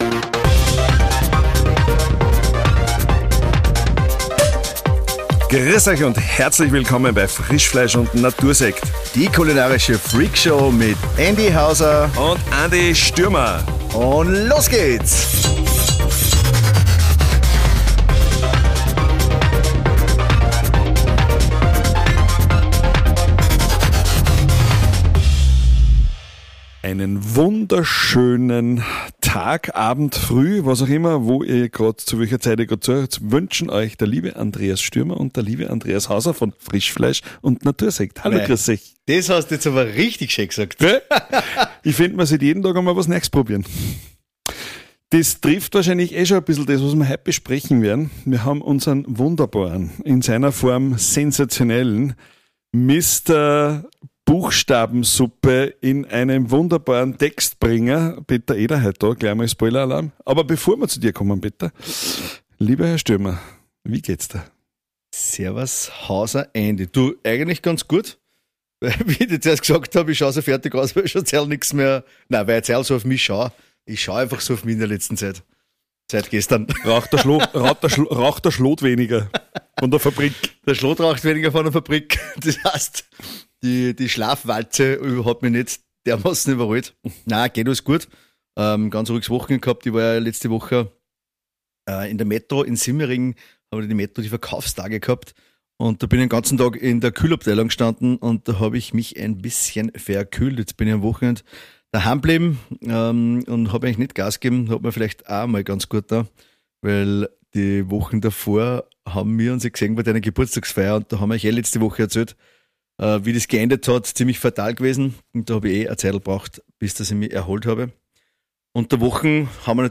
Ein Gespräch darüber, wie man sich selbst wieder besser steuert, bevor man versucht, ein Team oder ein Unternehmen zu führen.